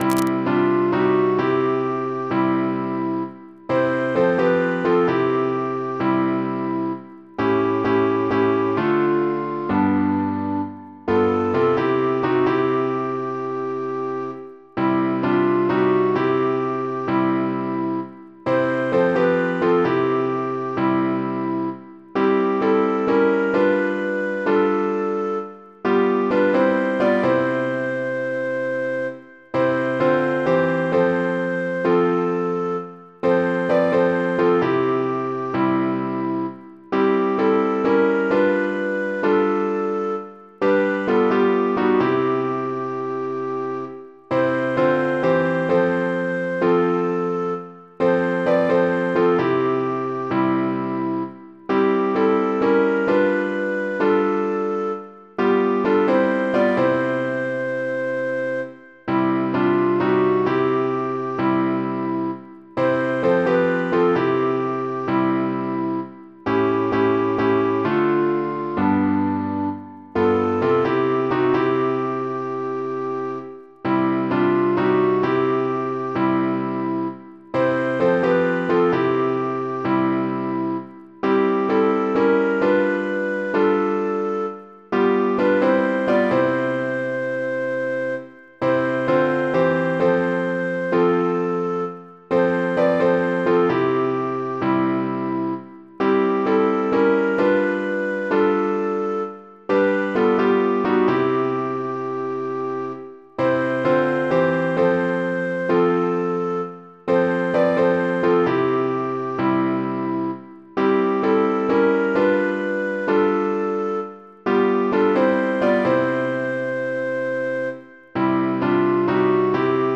piano, keyboard, keys
Мелодия за разучаване: